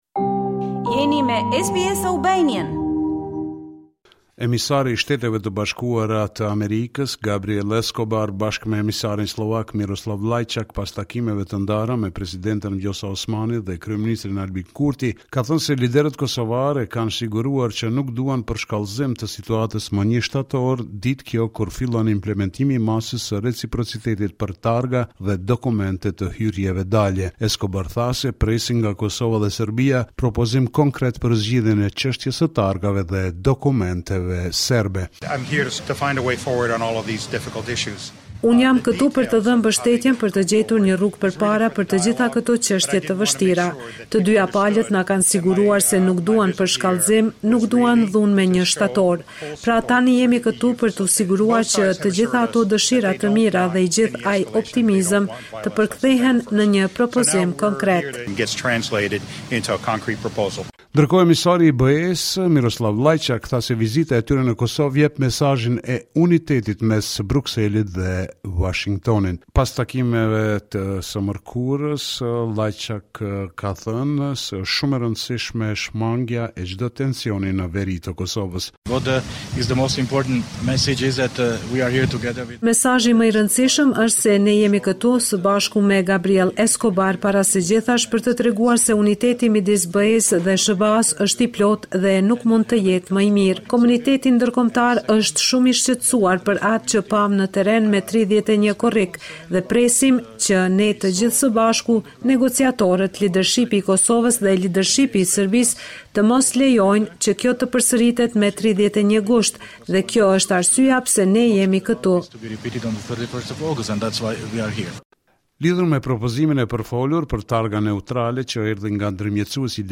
This is a report summarizing the latest developments in news and current affairs in Kosova.